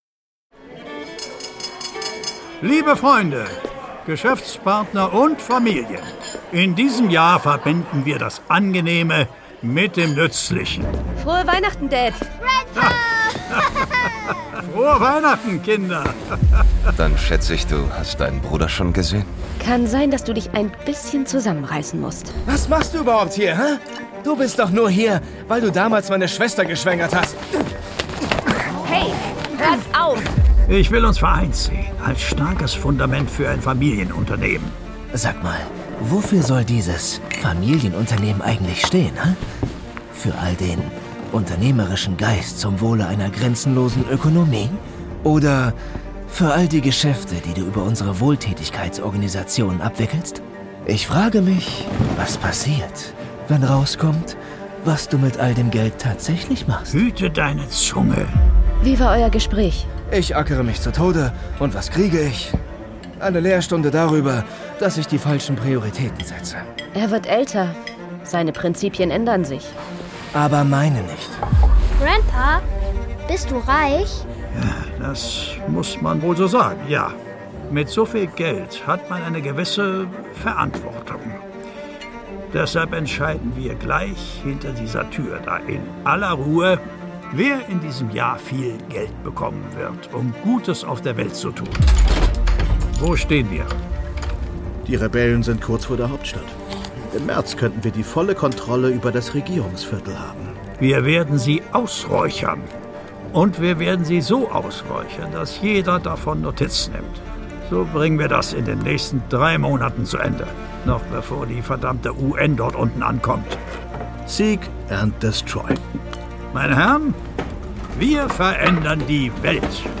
Letzte Episode des Audio-Blockbusters 2024: ASHFALL - Ein Thriller in vier Episoden!